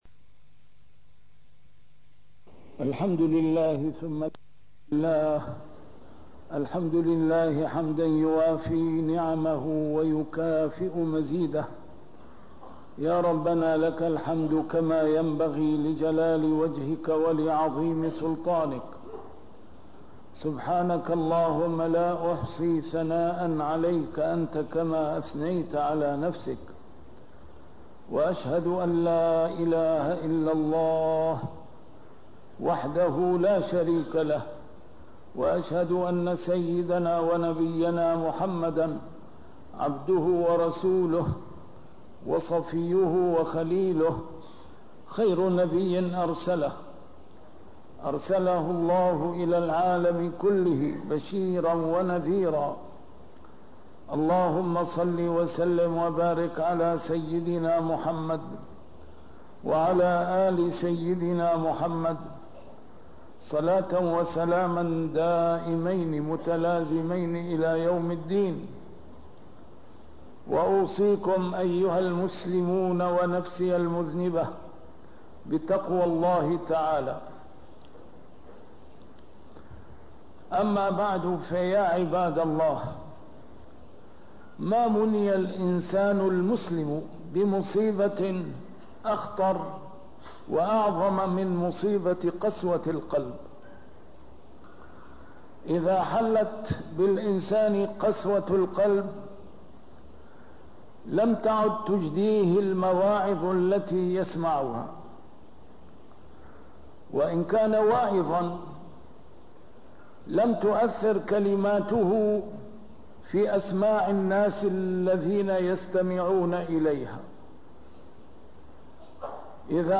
A MARTYR SCHOLAR: IMAM MUHAMMAD SAEED RAMADAN AL-BOUTI - الخطب - لهذا لم تعد تجدي المواعظ نفعاً